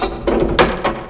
door_cl.wav